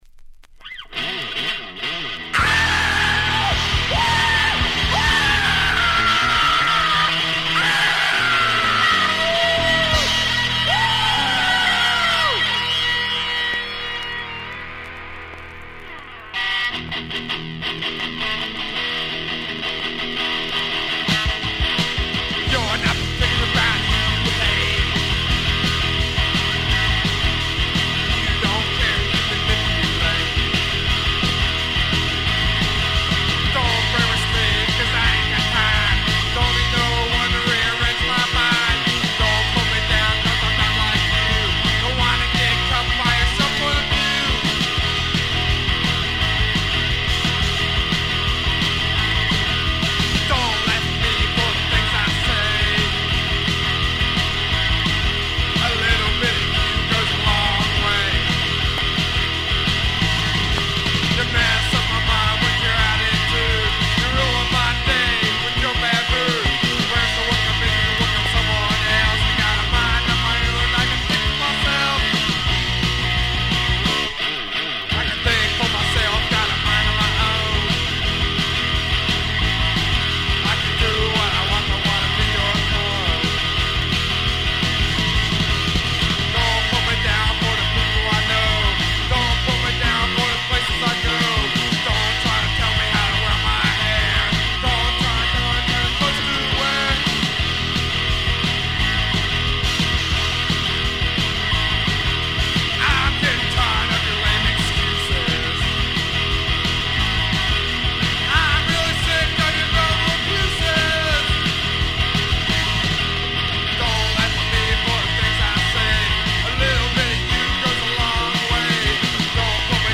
Garage/Roots/Slop-Rock bands